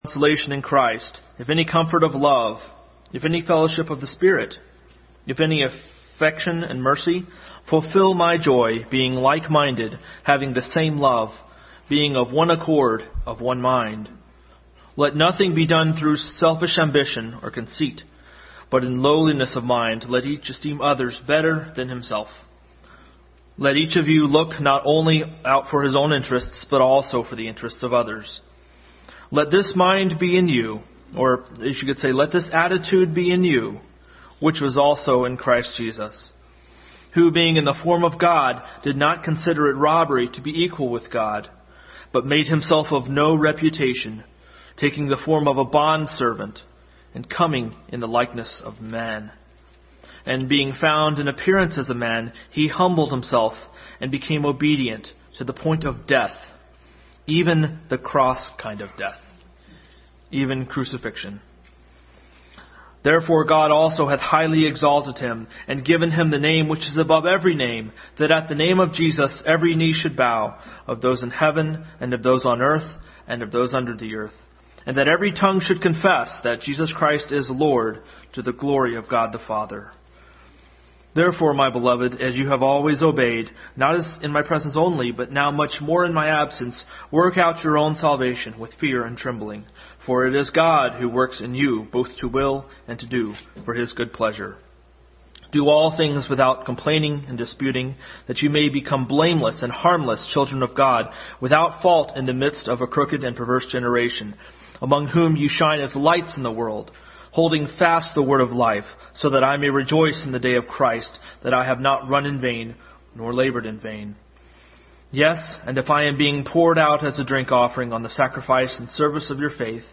2 Timothy 4:6 Service Type: Sunday School Continued study in Philippians.